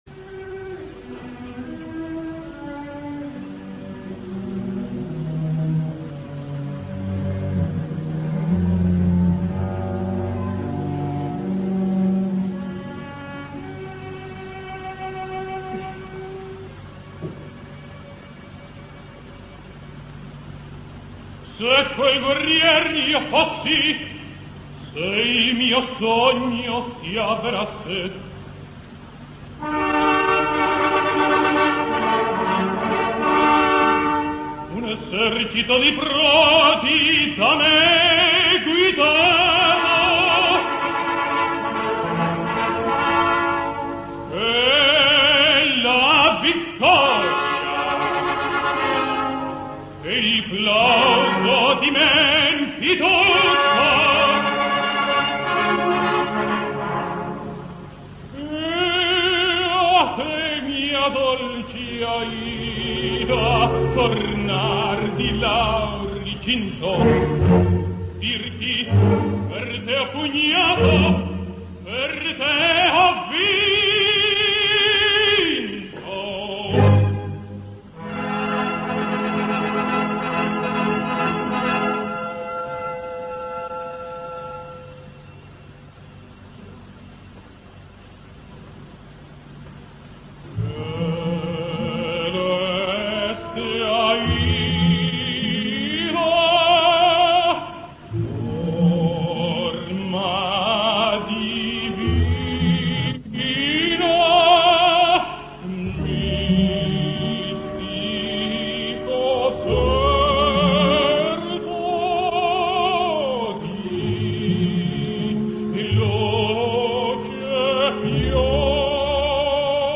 Alfredo Kraus sings Aida: